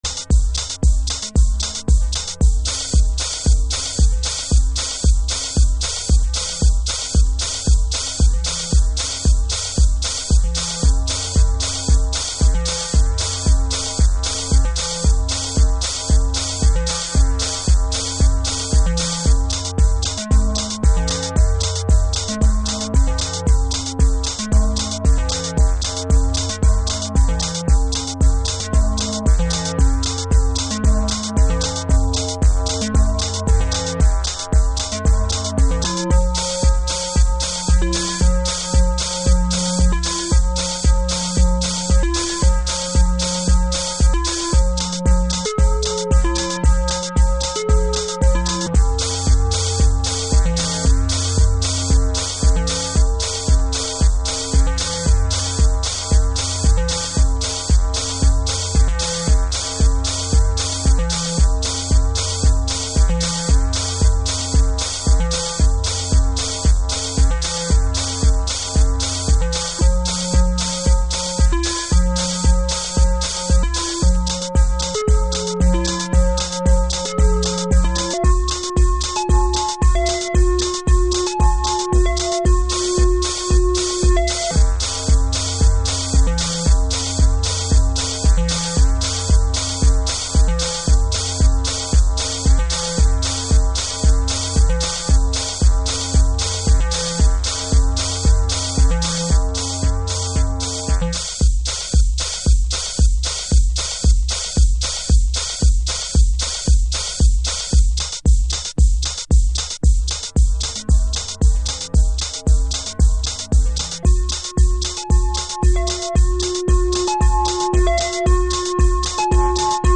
House / Techno
アーリーシカゴハウスの持っていた狂気だけを抽出したような、歪んだリズムトラックス。